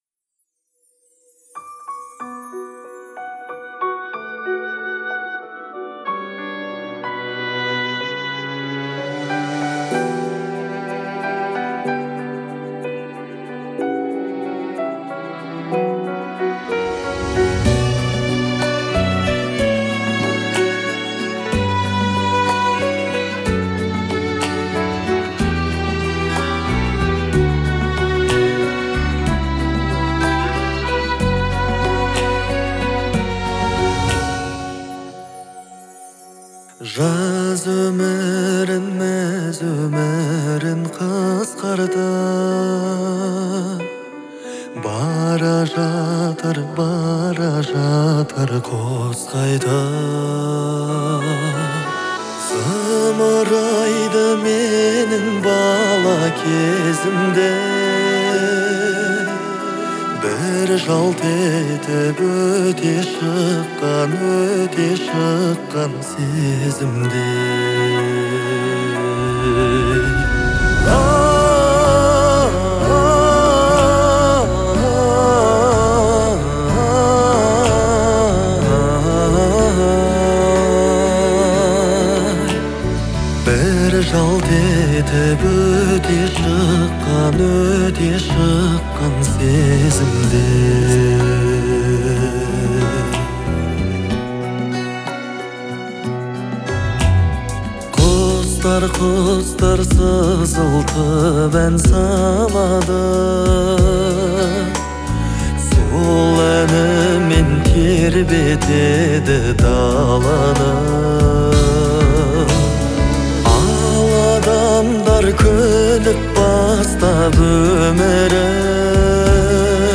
Звучание композиции пронизано чувственными мелодиями